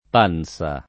Pansa [ p # n S a ]